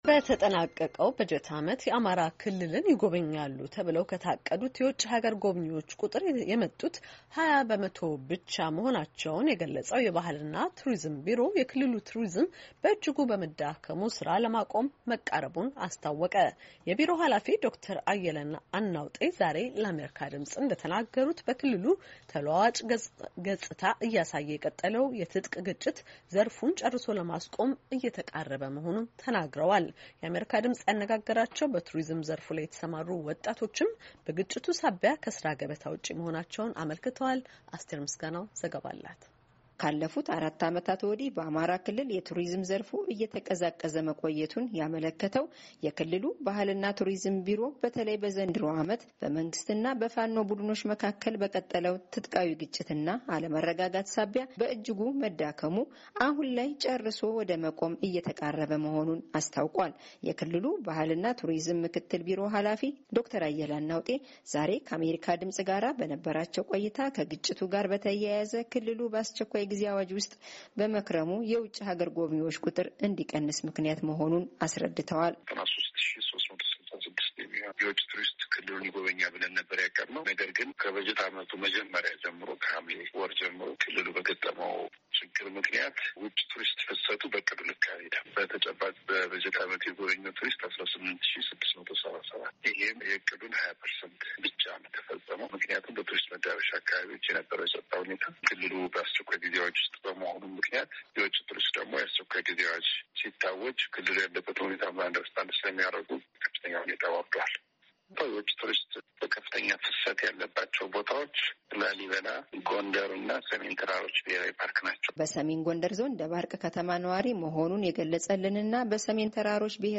የአሜሪካ ድምፅ ያነጋገራቸው በቱሪዝም ዘርፍ የተሰማሩ ወጣቶችም፣ በግጭቱ ሳቢያ ከሥራ ገበታ ውጭ መኾናቸውን አመልክተዋል፡፡ ዝርዝሩን ከተያያዘው ፋይል ይከታተሉ።